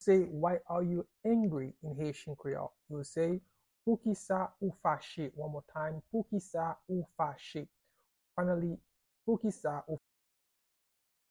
How-to-say-Why-are-you-angry-in-Haitian-Creole-–-Poukisa-ou-fache-pronunciation-by-a-Haitian-native.mp3